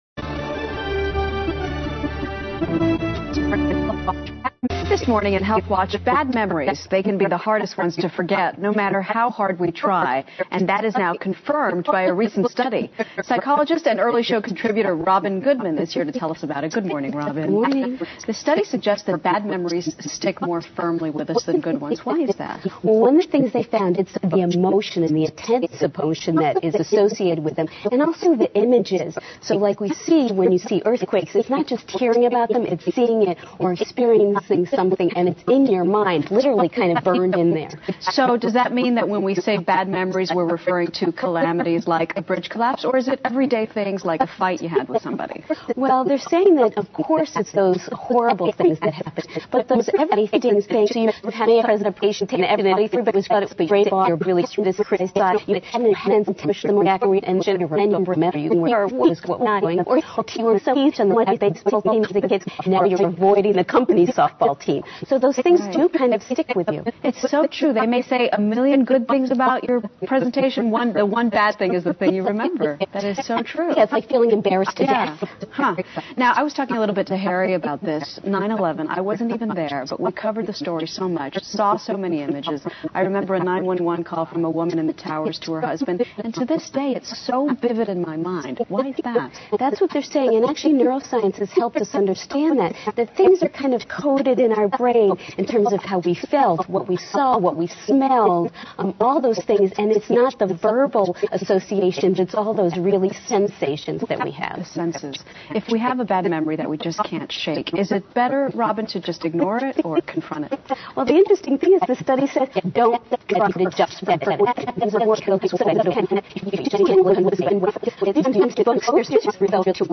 访谈录 Interview 2007-08-22&08-24, 苦闷的回忆，如何面对？ 听力文件下载—在线英语听力室